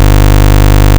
Out of phase by three-quarters cycle: